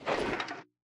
equip_gold5.ogg